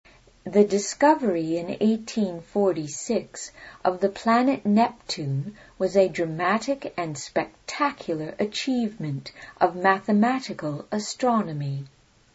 Location: USA
Are you a native English speaker? How do you pronounce this word?